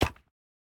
resin_brick_fall.ogg